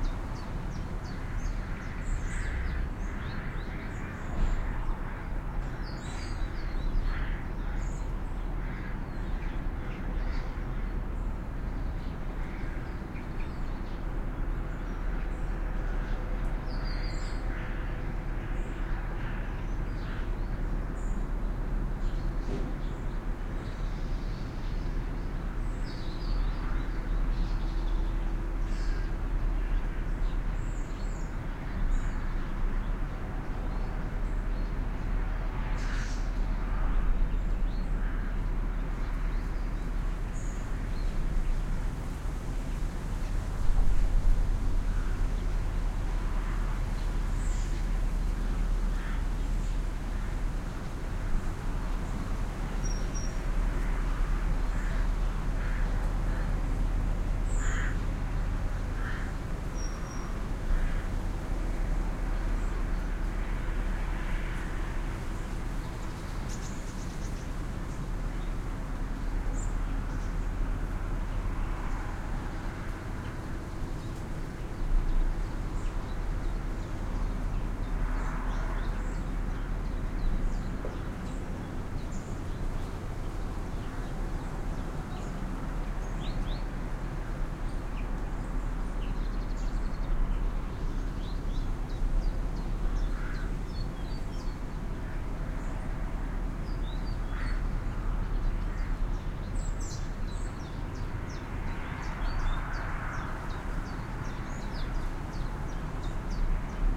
outdoorLoop.ogg